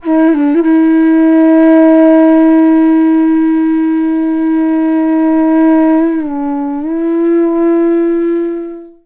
FLUTE-A05 -R.wav